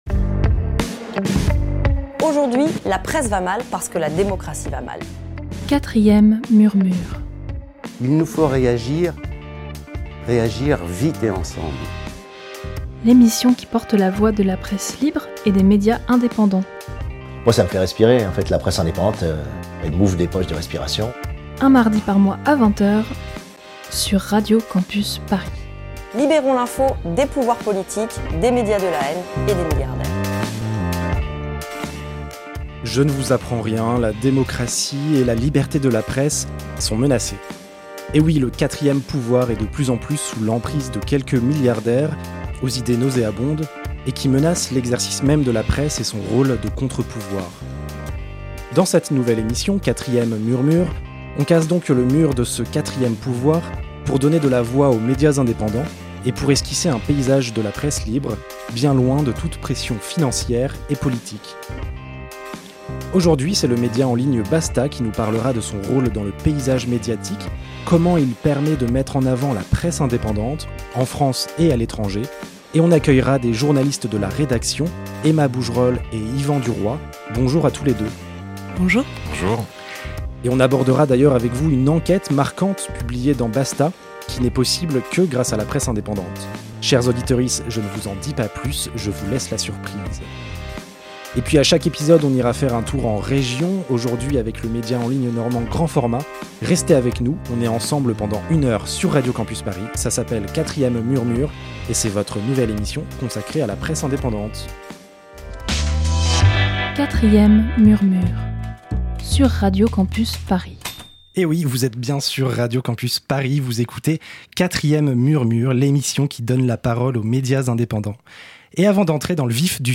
Pour cela, nous recevons en plateau le média en ligne Basta!.